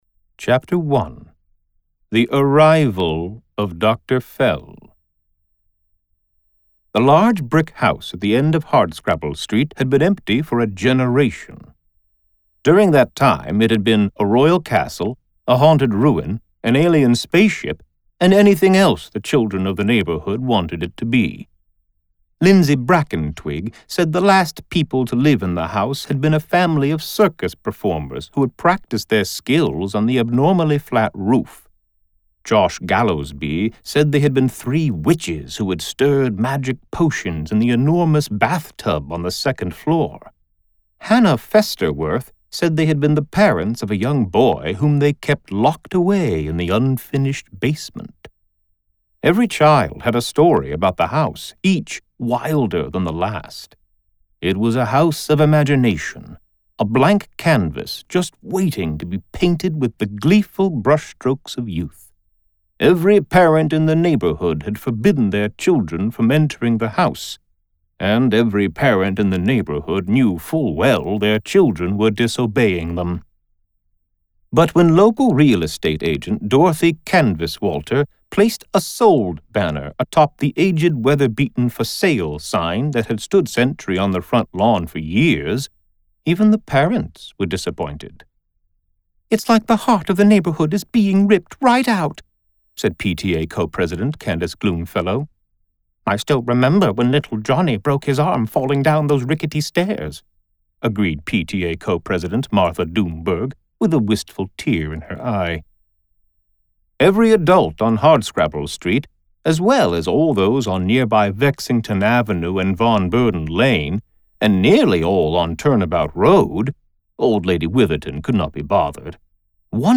I have recorded the audiobooks for both of my Middle Grade novels, Dr. Fell and the Playground of Doom and Beyond the Doors.